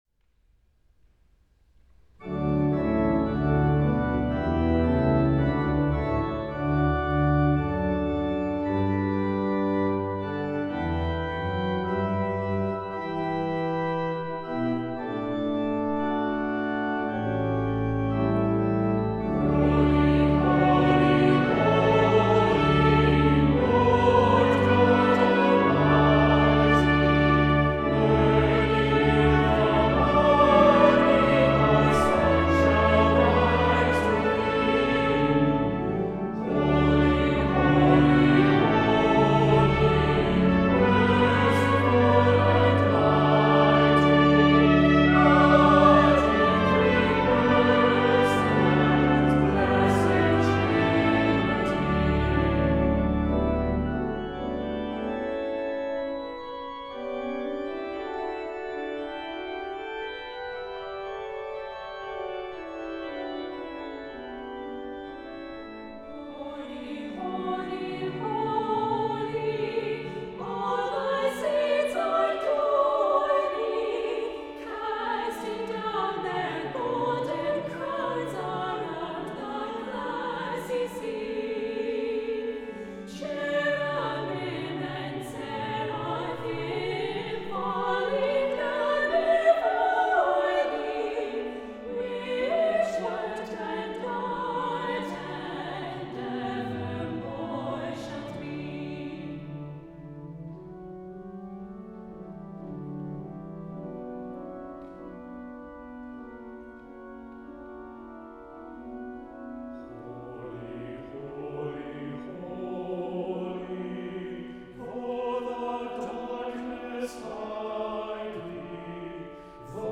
• Accompaniment: Trumpet